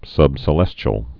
(sŭbsĭ-lĕschəl)